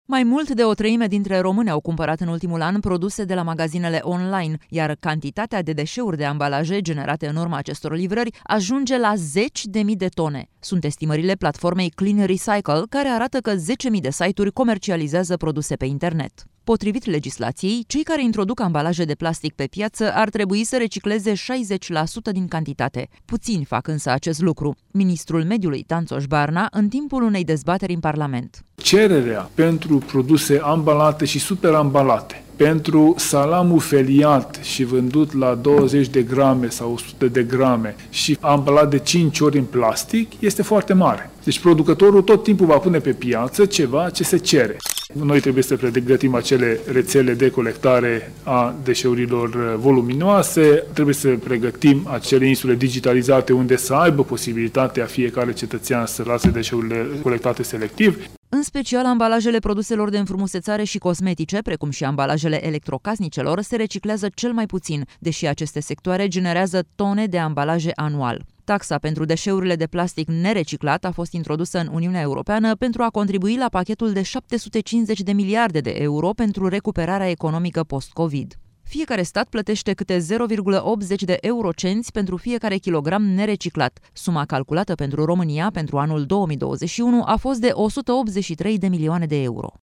Ministrul Mediului, Tanczos Barna, în timpul unei dezbateri în Parlament: